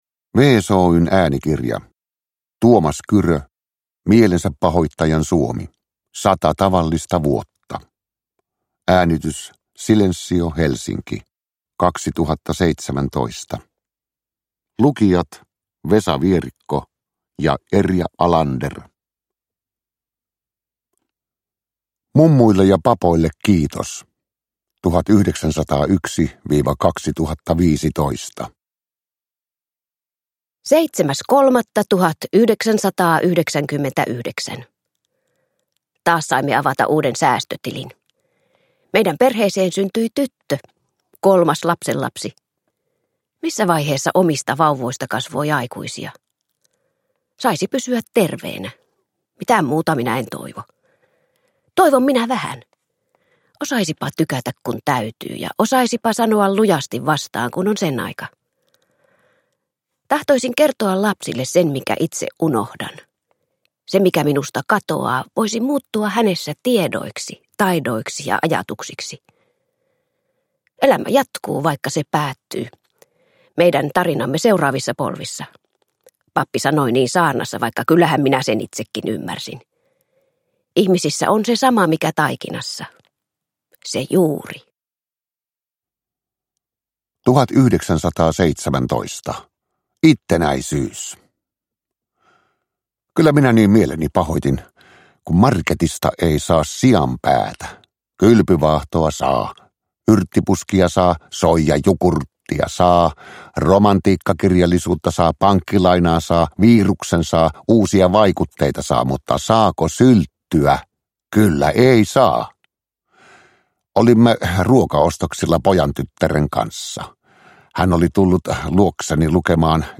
Mielensäpahoittajan Suomi – Ljudbok – Laddas ner